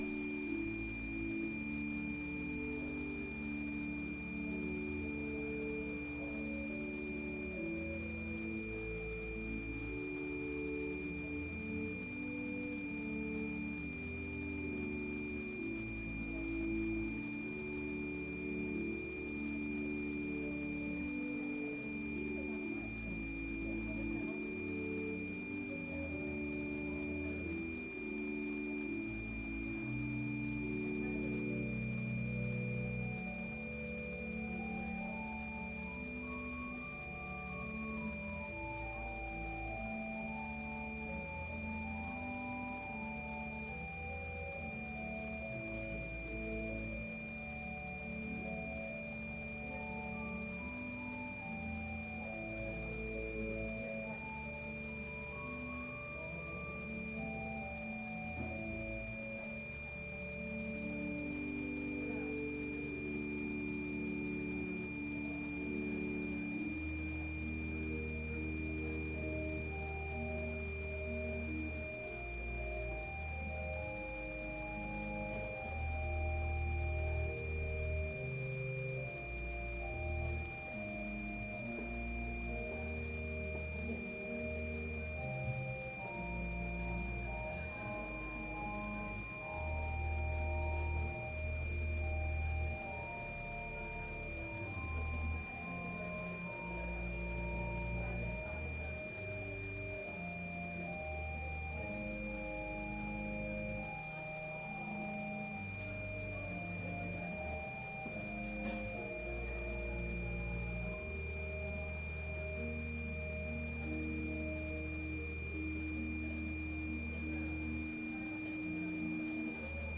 Sermons Archive - Christ Lutheran Church